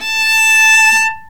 Index of /90_sSampleCDs/Roland - String Master Series/STR_Viola Solo/STR_Vla3 Arco nv
STR VIOLA 0L.wav